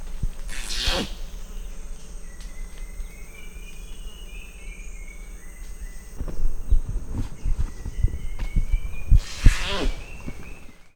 «Kiss-squeaks» hingegen sind ein Ausdruck von Angst oder Stress, die sie bei der Begegnung mit Forschenden, Nebelpardern oder Artgenossen ausgestossen.
Kiss-squeak